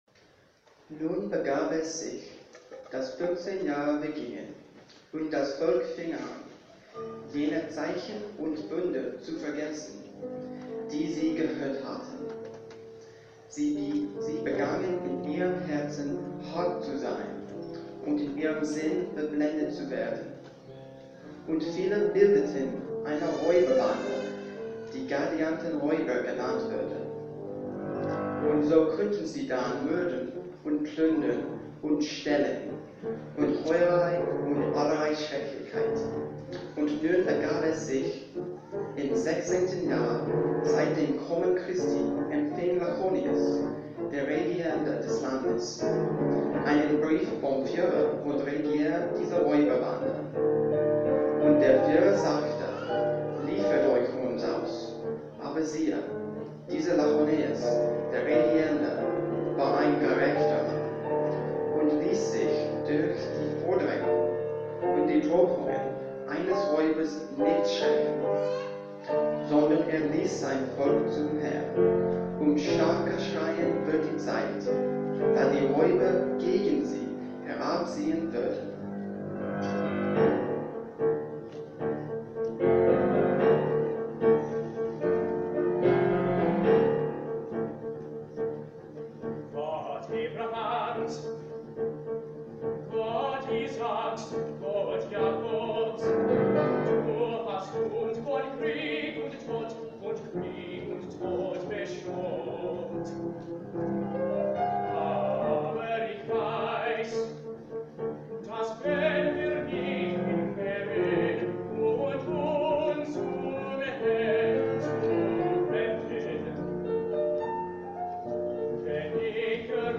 Baritone, TTBB Chorus, Narrator, and Piano